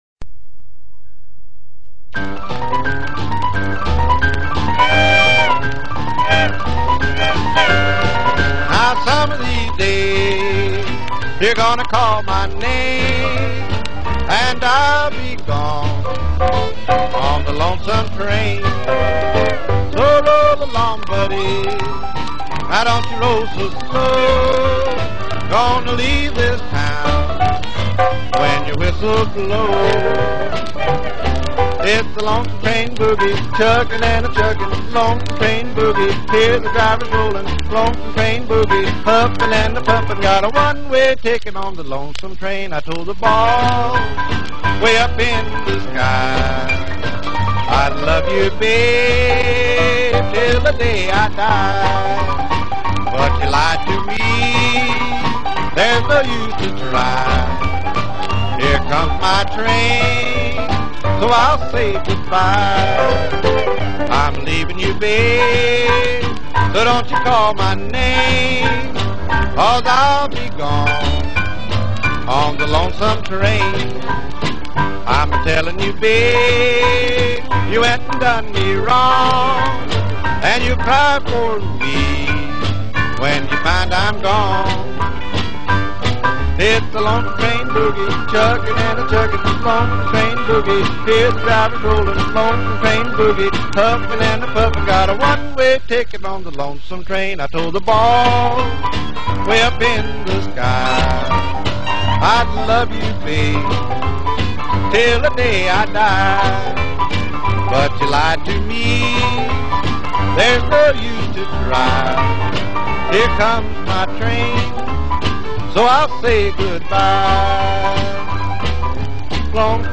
10" LP in a gatefold cover with rare country boogie.